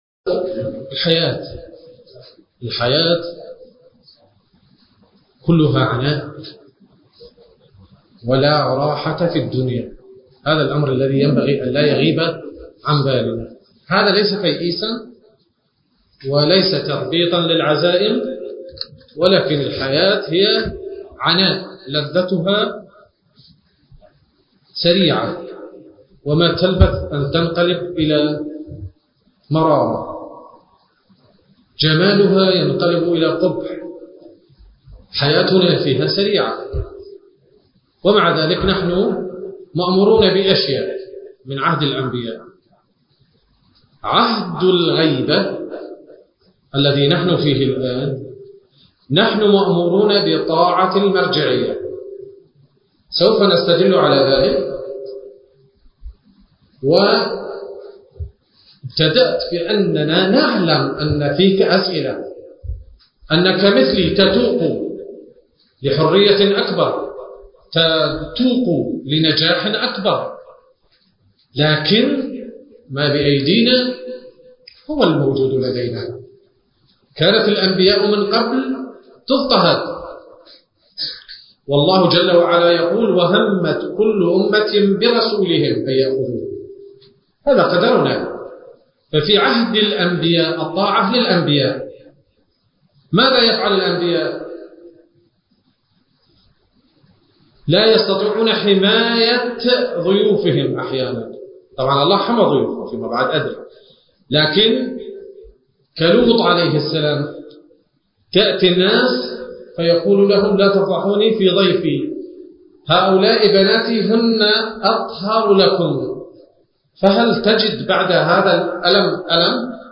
التاريخ: 1443 للهجرة المكان: الحسينية الحيدرية/ الكاظمية المقدسة